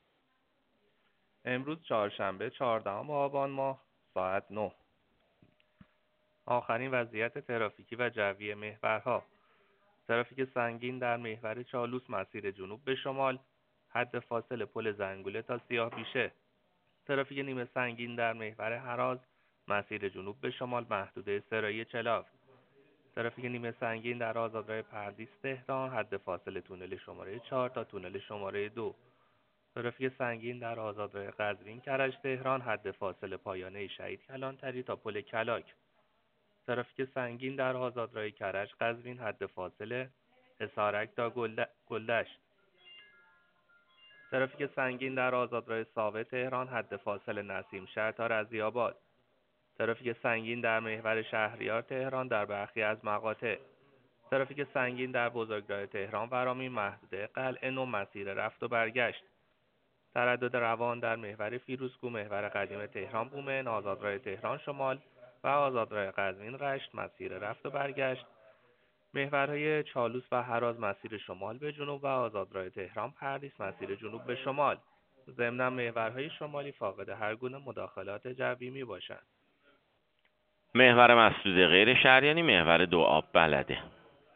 گزارش رادیو اینترنتی از آخرین وضعیت ترافیکی جاده‌ها ساعت ۹ چهاردهم آبان؛